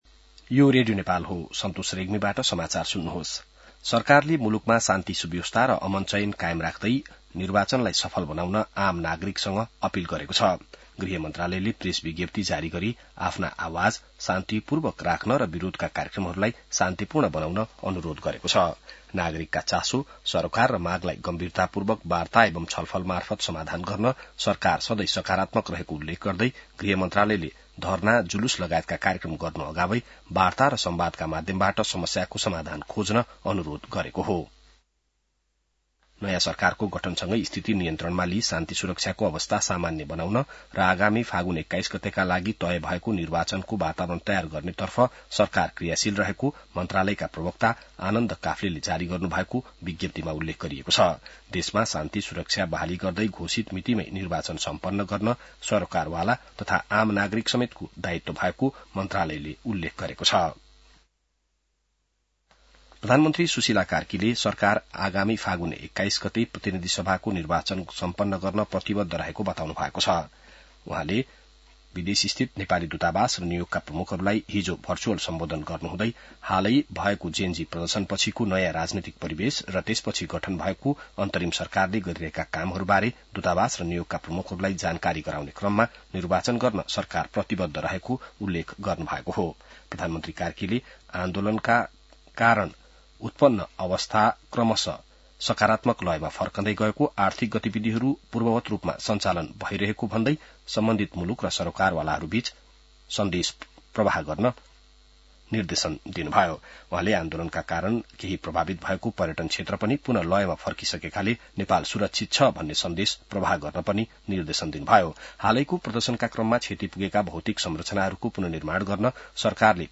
An online outlet of Nepal's national radio broadcaster
बिहान ६ बजेको नेपाली समाचार : २३ असोज , २०८२